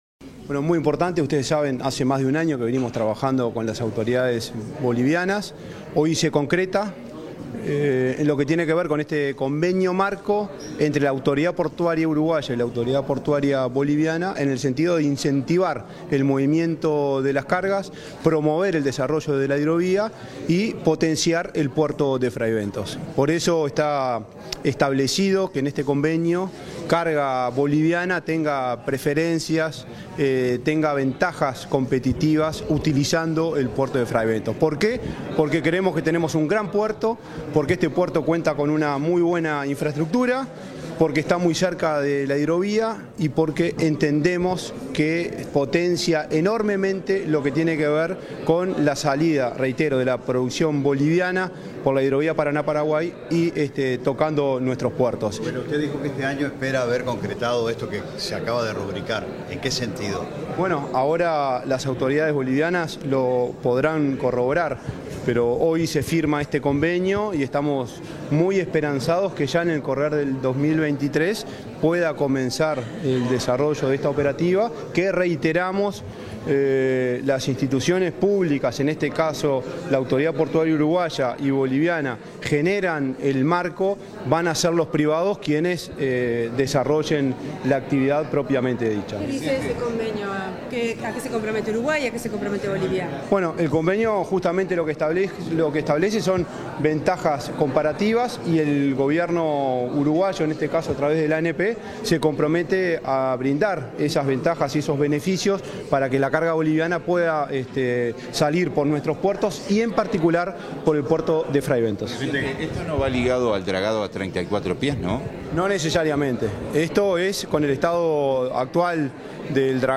Declaraciones del presidente de la ANP, Juan Curbelo
Declaraciones del presidente de la ANP, Juan Curbelo 07/02/2023 Compartir Facebook X Copiar enlace WhatsApp LinkedIn Tras la firma de un convenio con Bolivia para utilizar el puerto de Fray Bentos en régimen de puerto libre, este 7 de febrero. el presidente de la Administración Nacional de Puertos (ANP), Juan Curbelo, realizó declaraciones a la prensa.